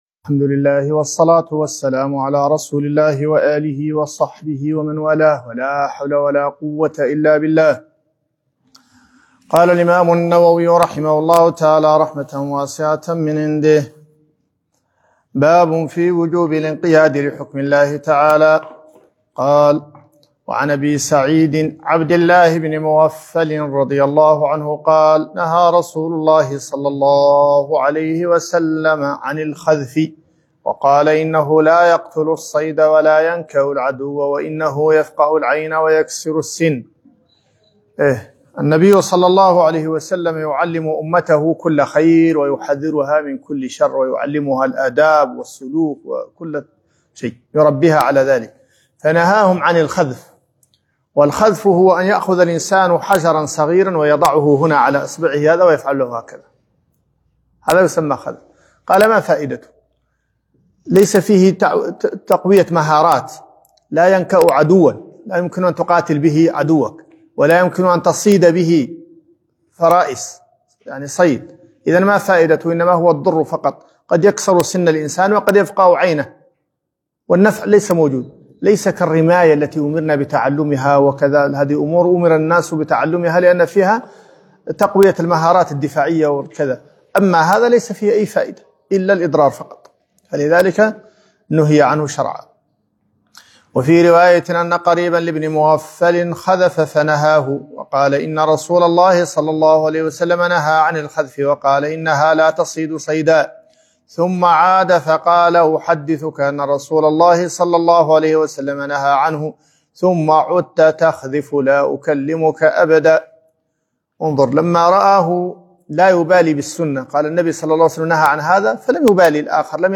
رياض الصالحين الدرس 14